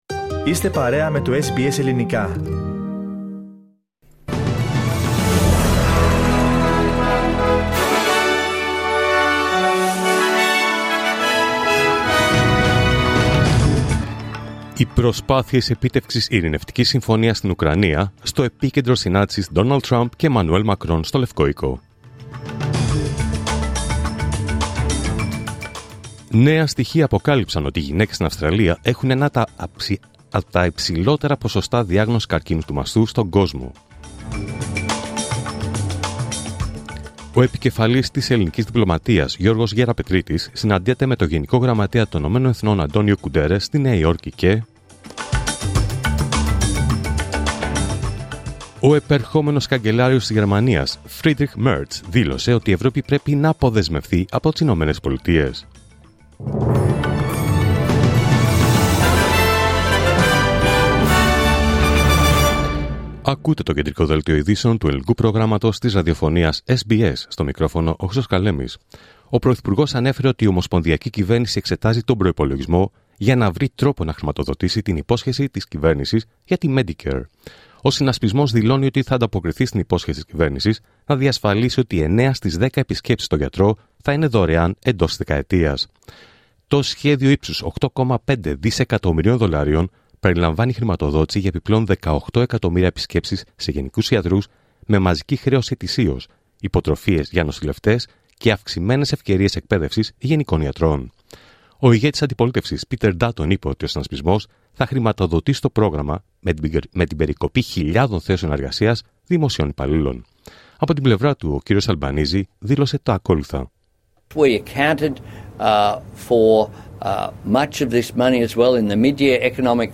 Δελτίο Ειδήσεων Τρίτη 25 Φεβρουάριου 2025